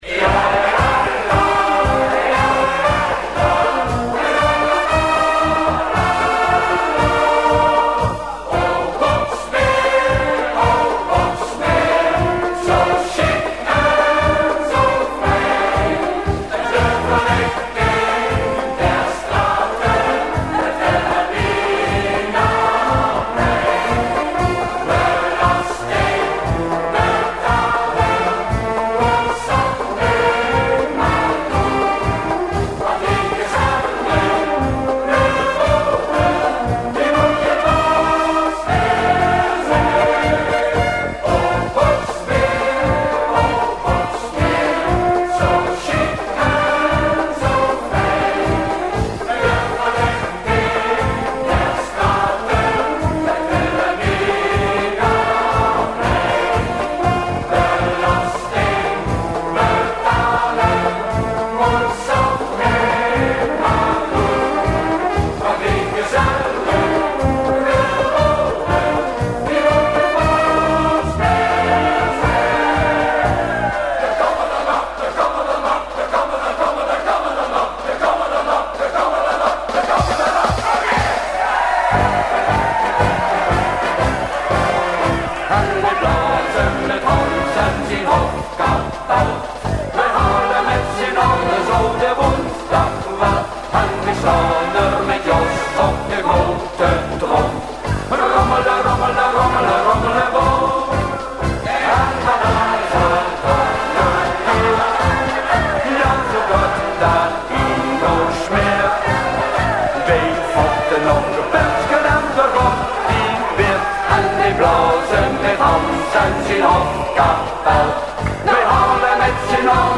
blaaskapel